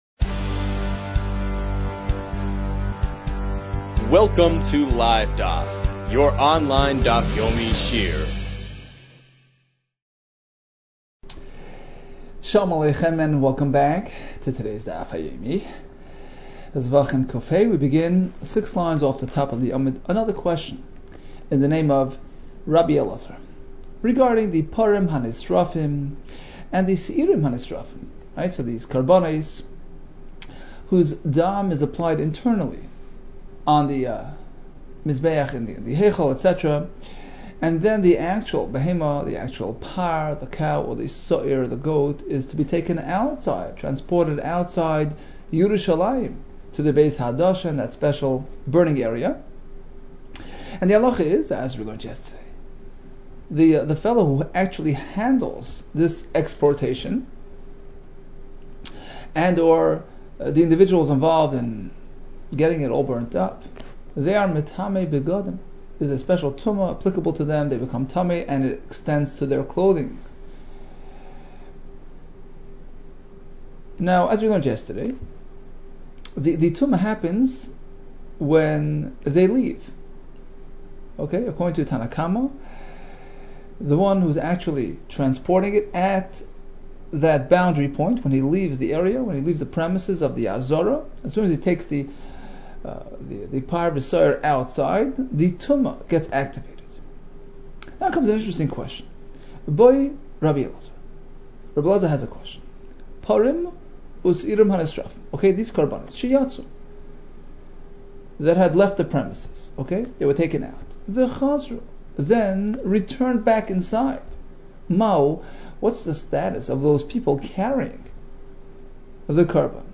Zevachim 105 - זבחים קה | Daf Yomi Online Shiur | Livedaf